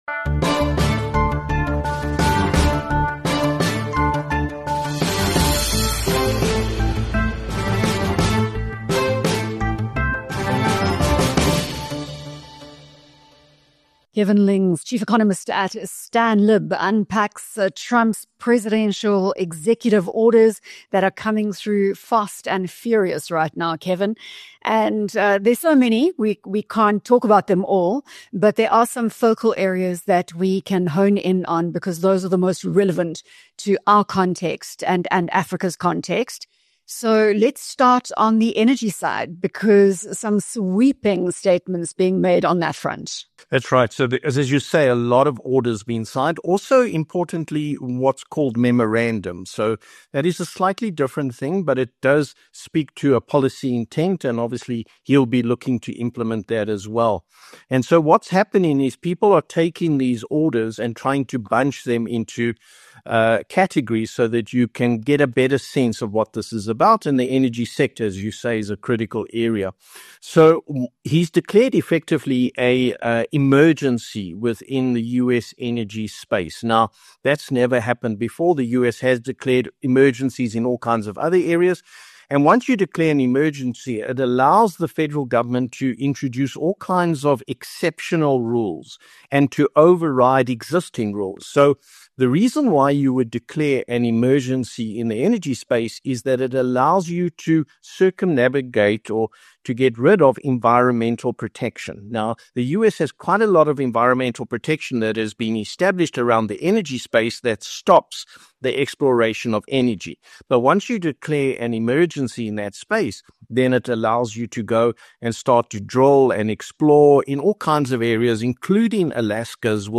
Welcome to BizNews Radio where we interview top thought leaders and business people from South Africa and across the globe.